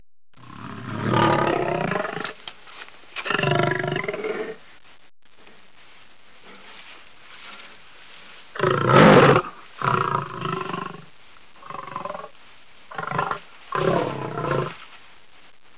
Lion.wav